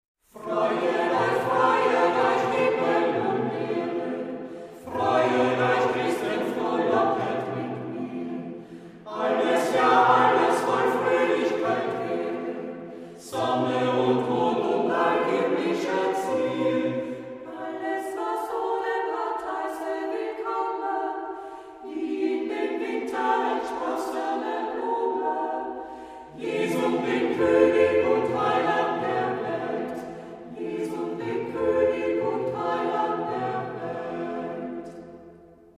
contribute to a contemplative atmosphere